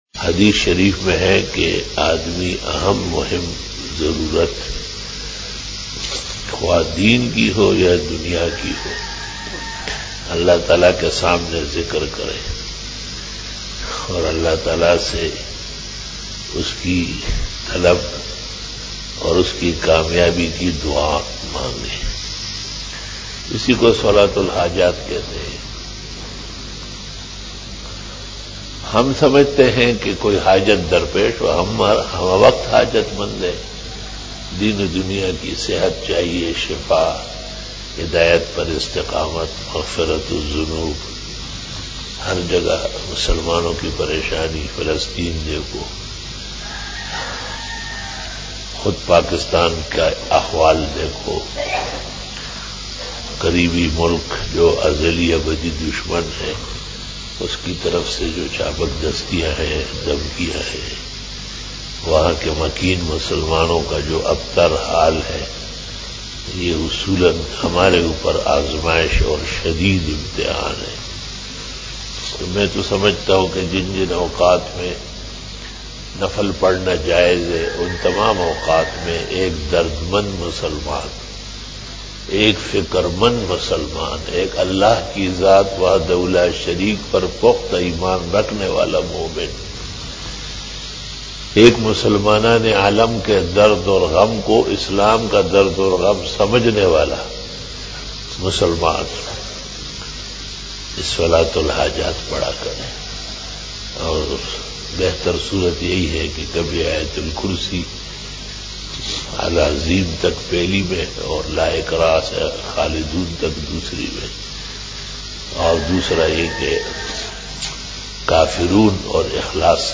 After Fajar Byan
بیان بعد نماز فجر بروز جمعہ